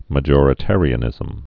(mə-jôrĭ-târē-ə-nĭzəm, -jŏr-)